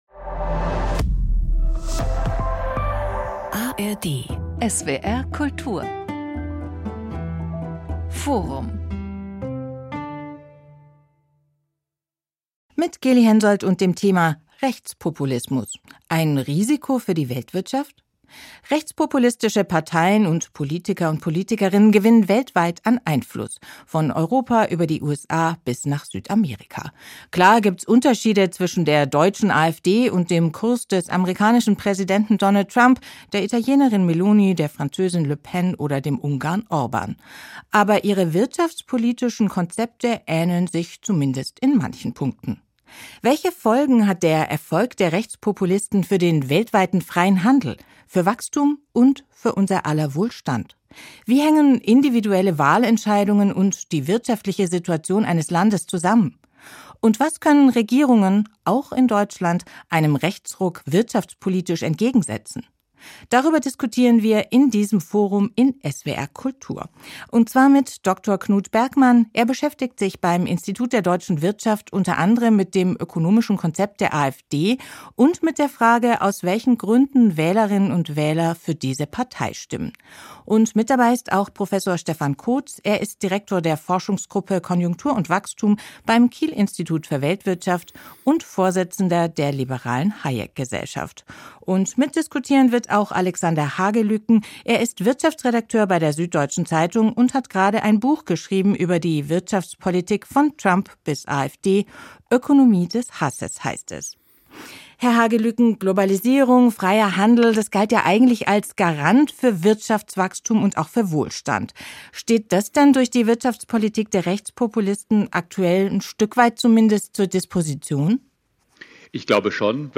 diskutiert